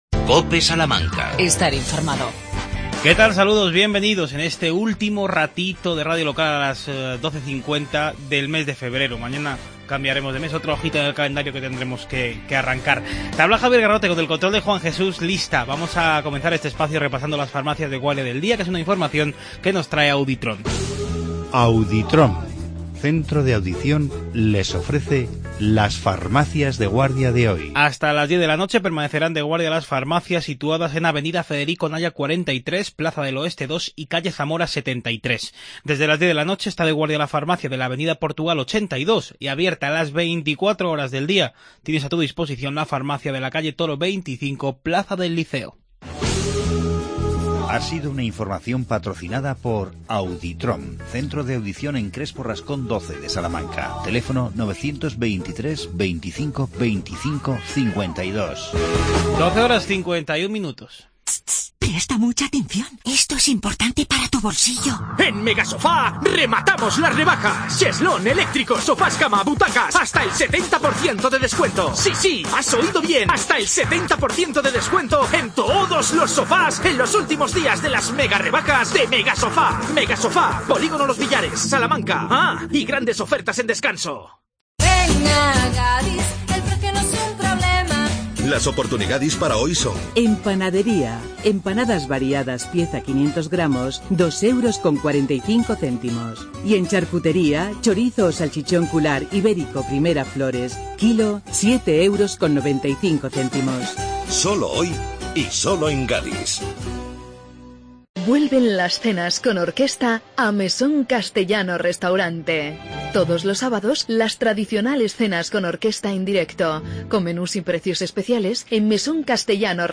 AUDIO: Charlamos con el concejal de Patrimonio Carlos García Carbayo sobre las mejoras que acomete y acometerá el Ayuntamiento.